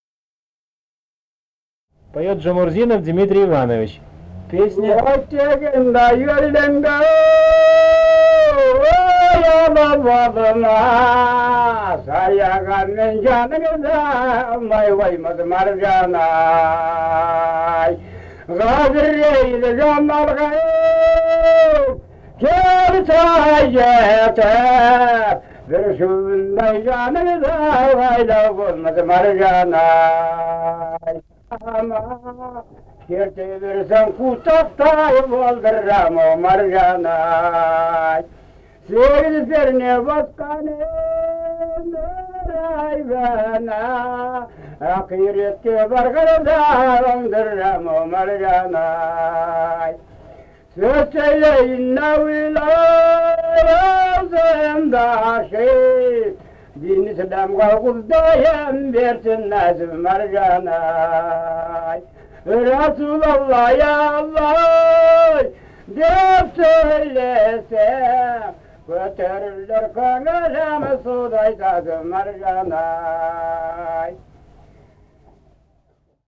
полевые материалы
«Маржан-ай (Маржан қыз)» (казахская авторская).
Алтайский край, д. Алексеевка Чарышского района, 1967 г. И1020-01